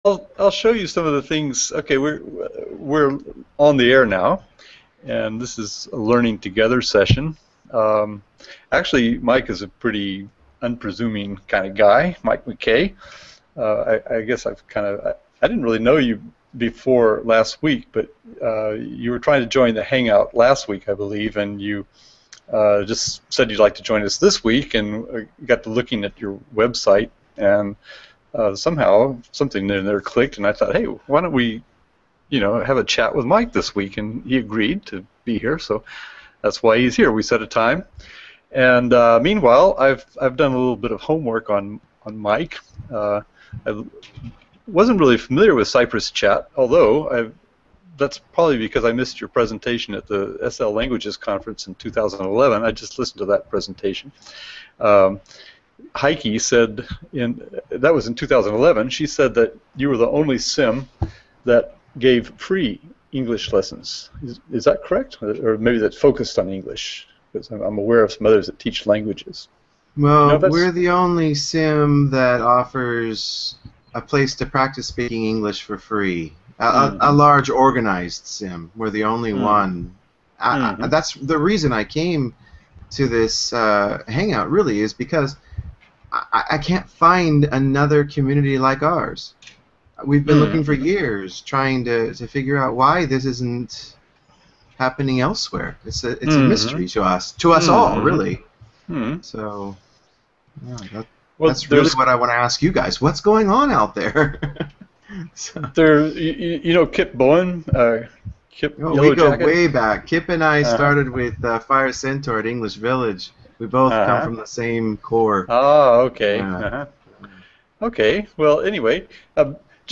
A lovely conversation